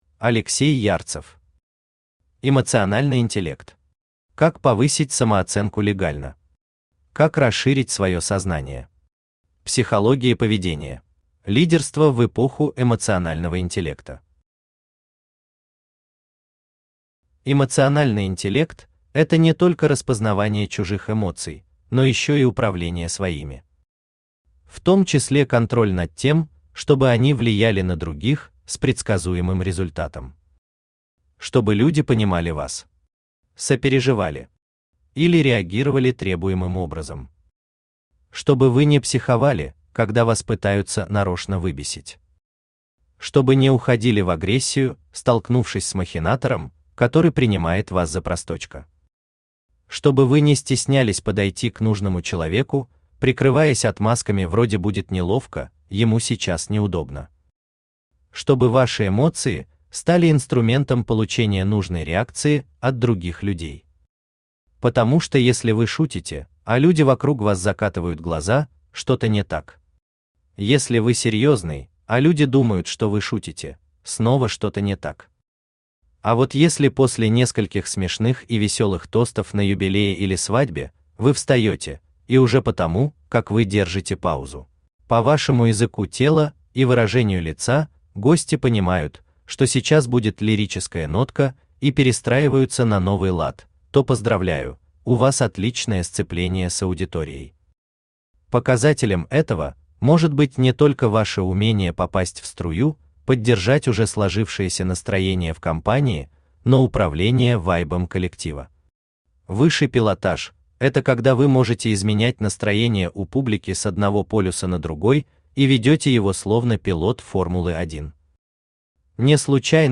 Аудиокнига Эмоциональный интеллект.
Психология поведения Автор Алексей Валерьевич Ярцев Читает аудиокнигу Авточтец ЛитРес.